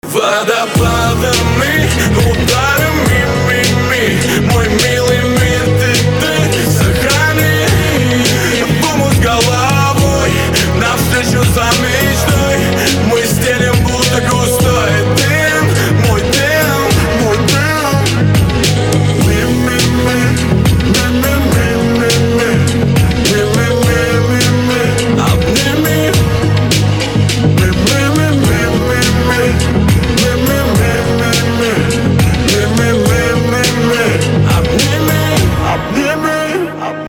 лирика
Хип-хоп
спокойные
красивая мелодия
романтика
христианский рэп